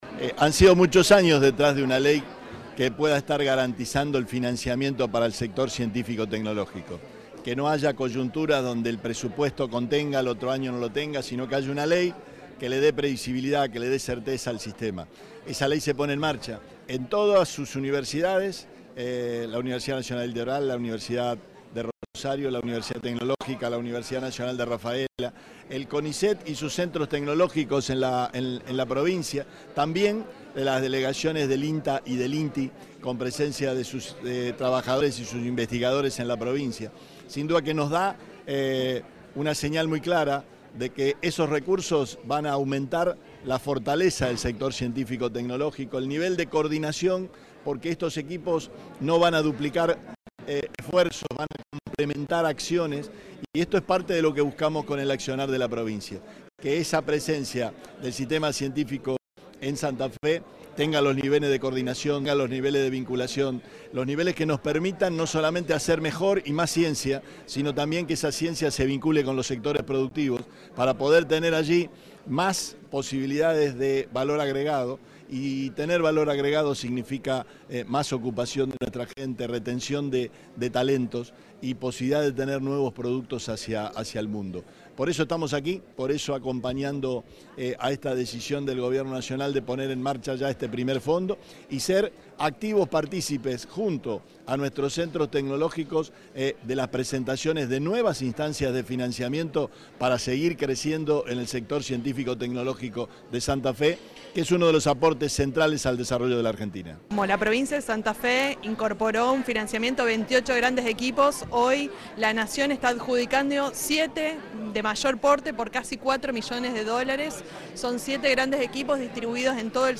Declaraciones de Perotti y Baima